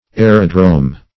Aerodrome \A"["e]*ro*drome`\, n. [A["e]ro- + Gr. ? a running.]